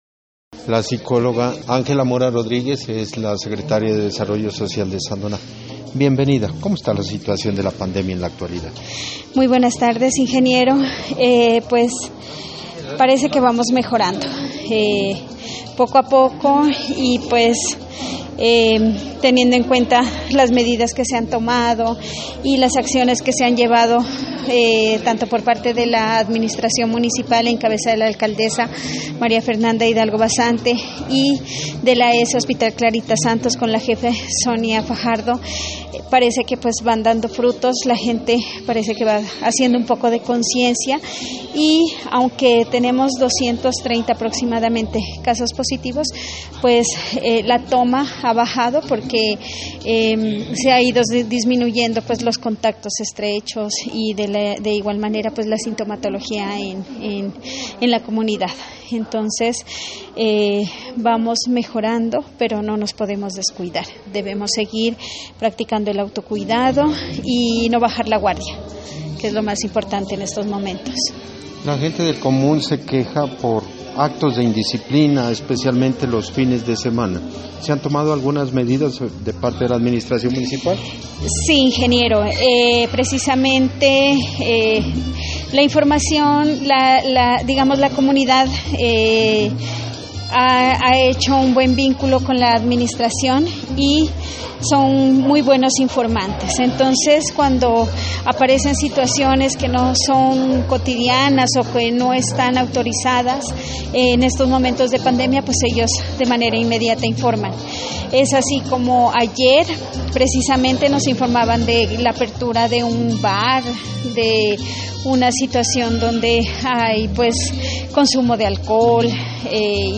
Entrevista con la secretaria de desarrollo social, realizada el sábado: